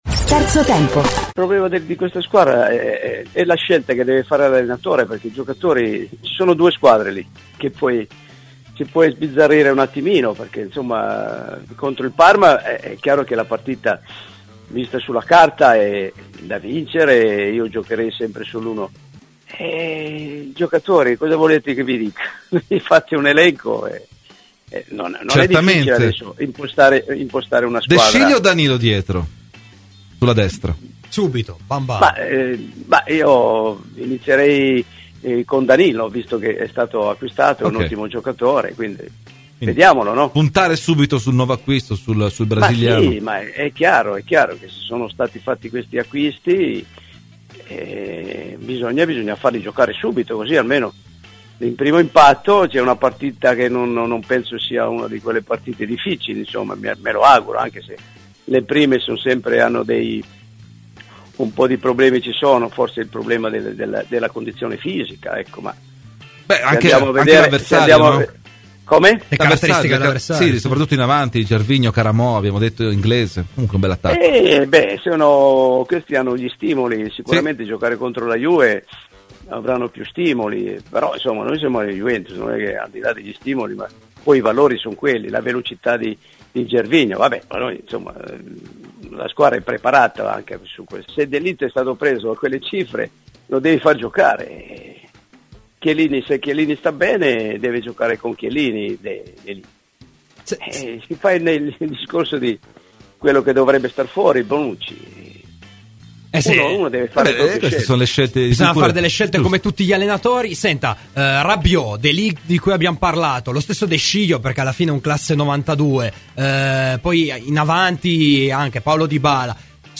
Ai microfoni di Radio Bianconera, nel corso di ‘Terzo Tempo’, è intervenuto l’ex bianconero Antonello Cuccureddu: “La partita contro il Parma sulla carta è da vincere, Sarri può scegliere quasi tra due squadre.